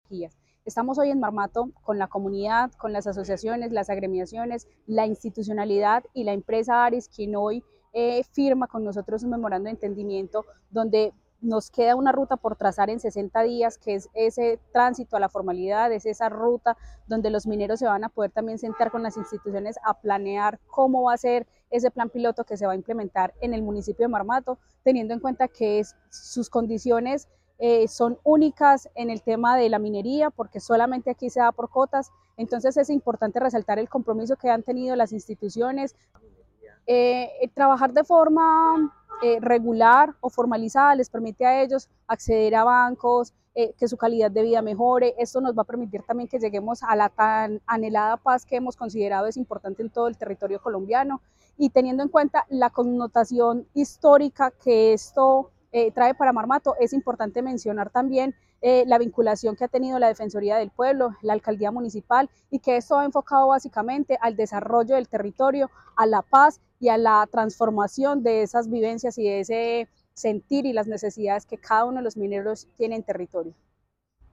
Yulieth Loaiza, Directora de Formalización Minera del Ministerio de Minas y Energía.